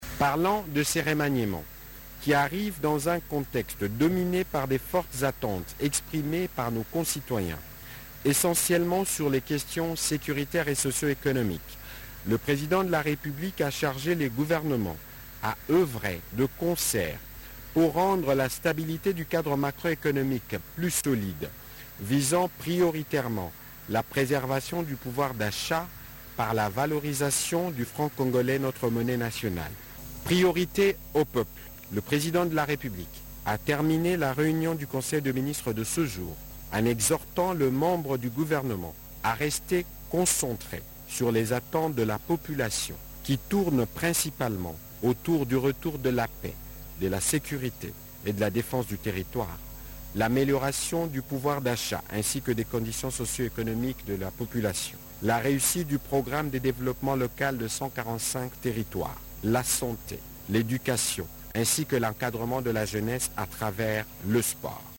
Vous pouvez écouter Patrick Muyaya dans cet extrait du compte rendu du Conseil des ministres lu à la télévision nationale congolaise :